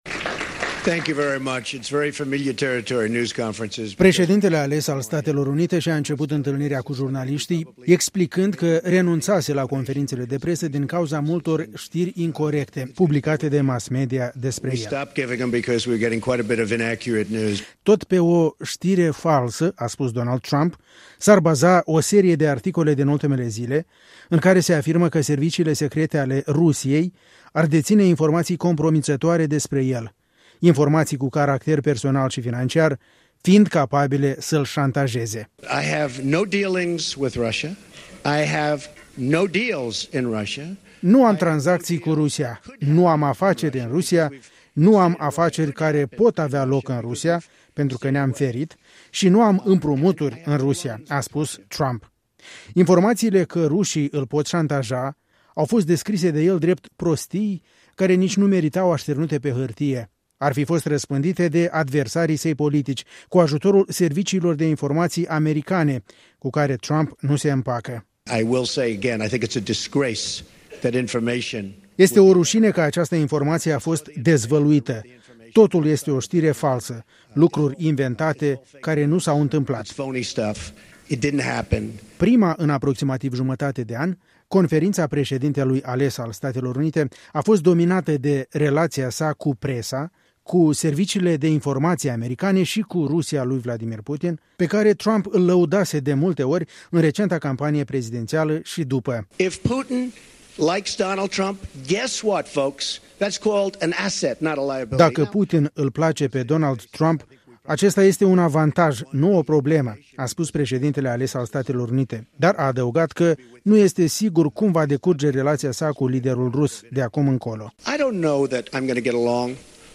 Conferința de presă a președintelui-ales american Donald Trump